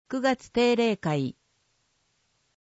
声の市議会だより
なお、この音声は「音訳グループまつさか＜外部リンク＞」の皆さんの協力で作成しています。